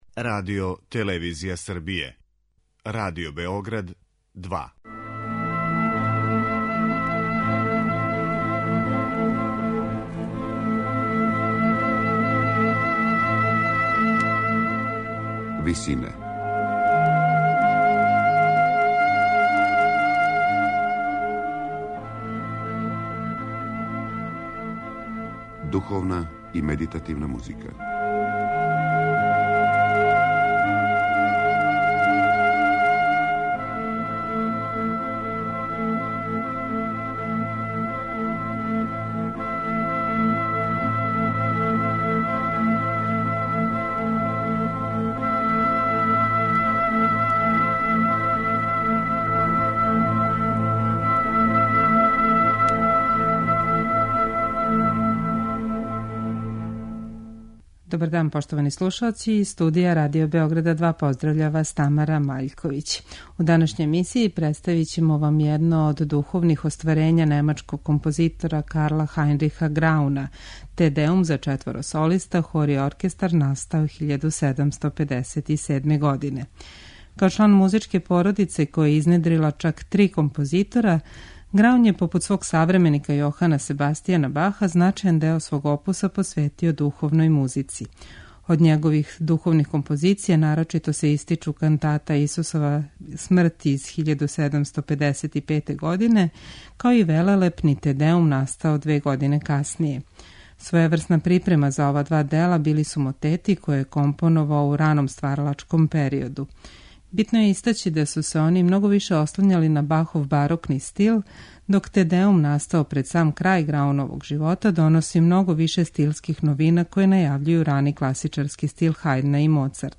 У данашњој емисији представићемо вам једно од духовних остварења немачког композитора Карла Хајнриха Грауна, Te Deum за четворо солиста, хор и оркестар настао 1757. године.
сопран
тенор